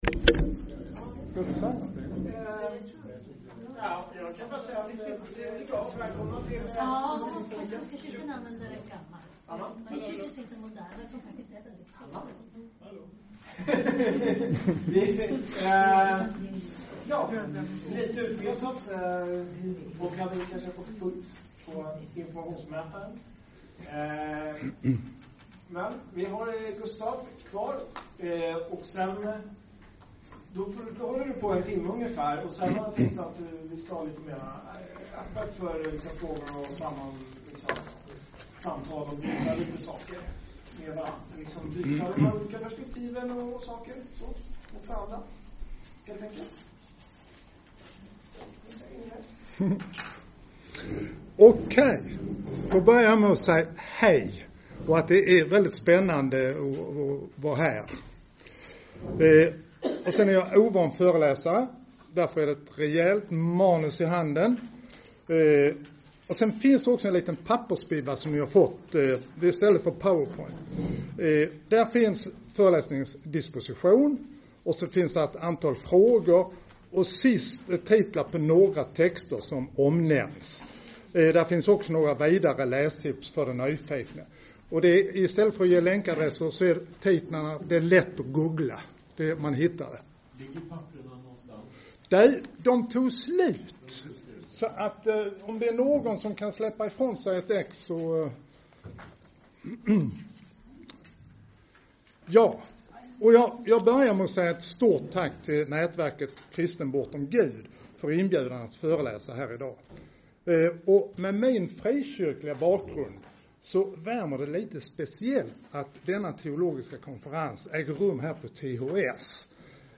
Bortom det �vernaturliga. Teologisk konferens p� Teologiska H�gskolan i Stockholm i oktober 2017